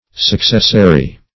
Search Result for " successary" : The Collaborative International Dictionary of English v.0.48: Successary \Suc"ces*sa*ry\, n. Succession.